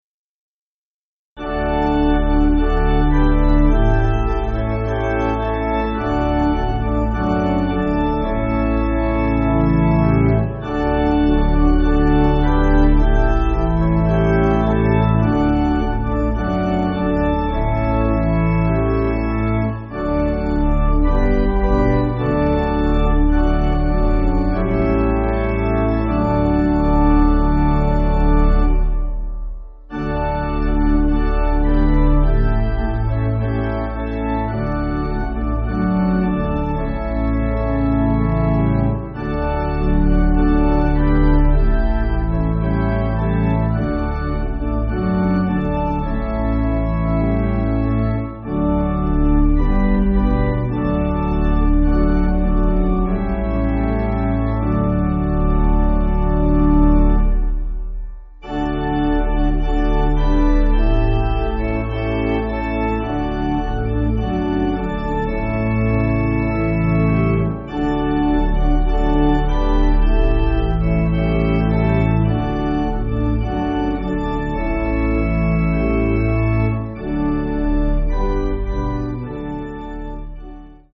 Danish Hymn